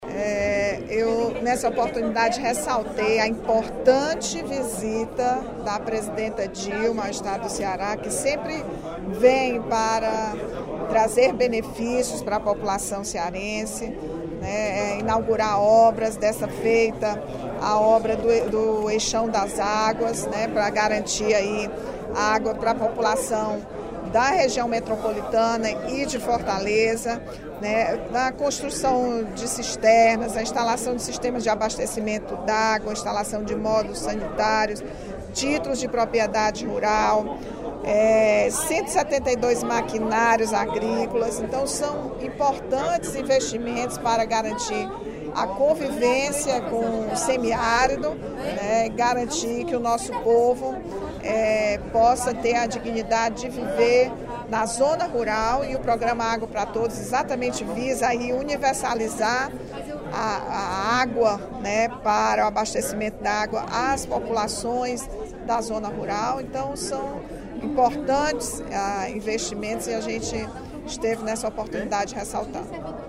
No primeiro expediente da sessão plenária desta terça-feira (18/03), a deputada Rachel Marques (PT) criticou a forma como o deputado Fernando Hugo (SDD) se dirigiu à vinda da presidente Dilma ao Ceará.